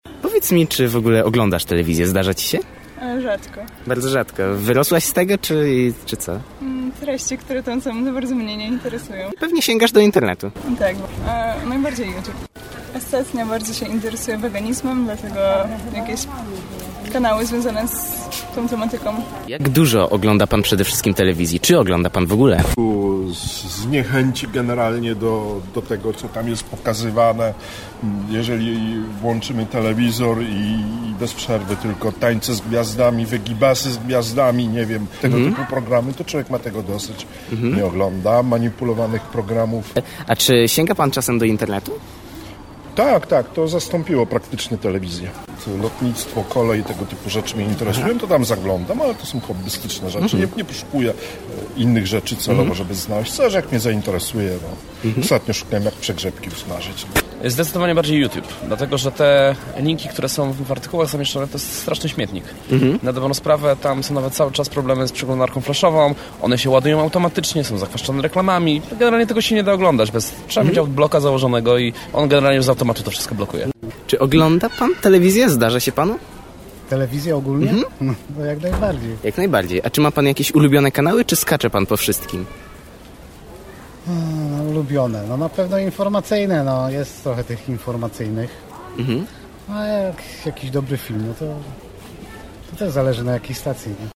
Zapytaliśmy mieszkańców Zielonej Góry o ich podejście do telewizji oraz o to, co najczęściej oglądają w sieci.